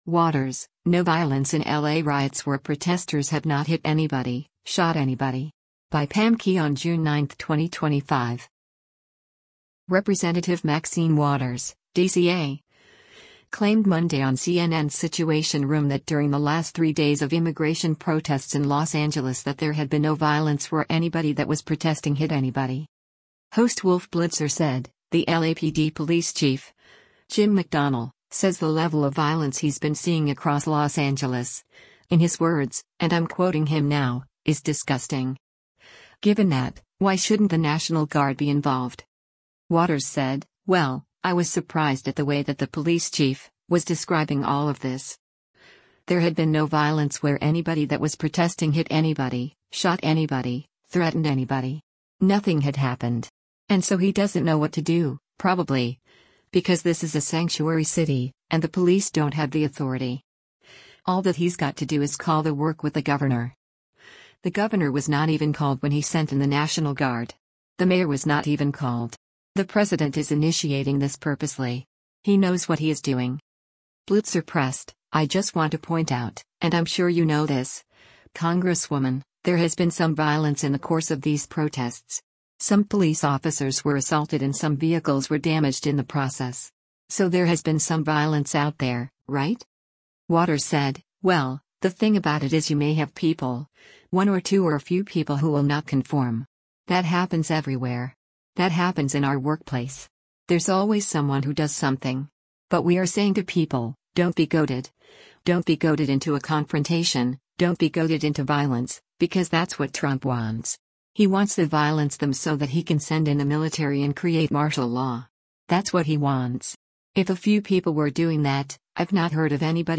Representative Maxine Waters (D-CA) claimed Monday on CNN’s “Situation Room” that during the last three days of immigration protests in Los Angeles that “there had been no violence where anybody that was protesting hit anybody.”